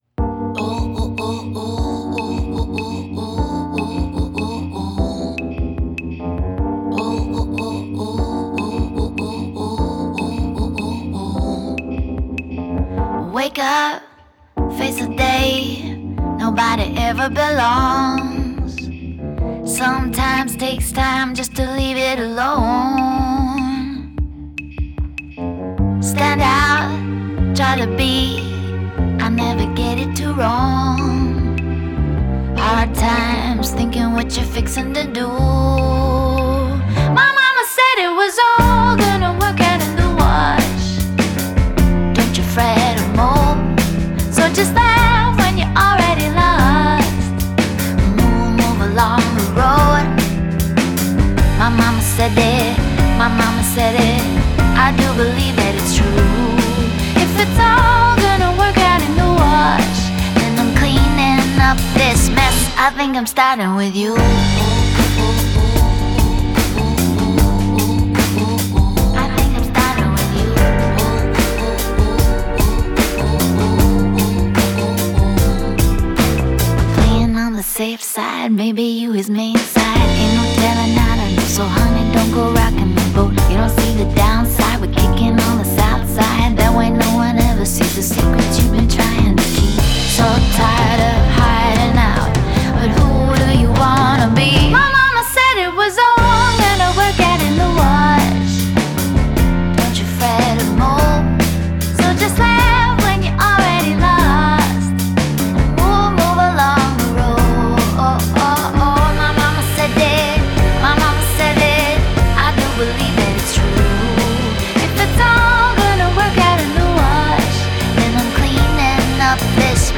Genre: Singer/Songwriter, Indie Pop, Rock, Folk